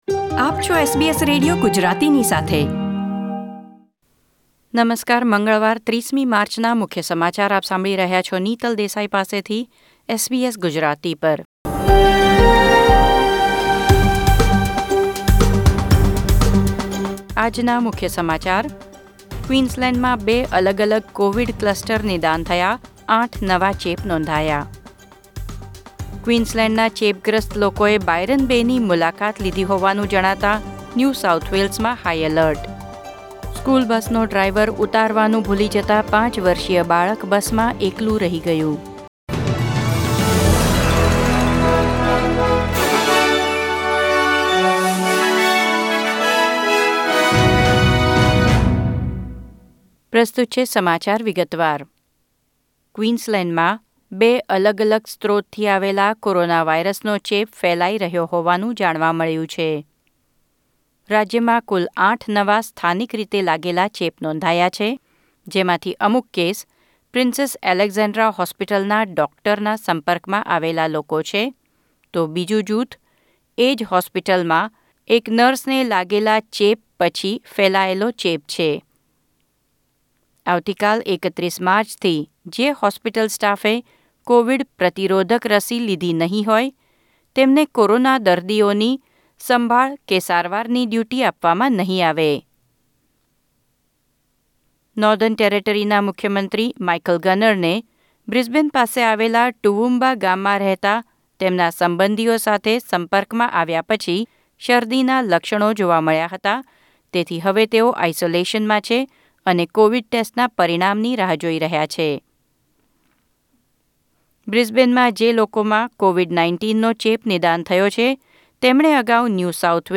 ૩૦ માર્ચ ૨૦૨૧ના મુખ્ય સમાચાર